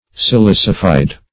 Silicified \Si*lic"i*fied\, a. (Chem.)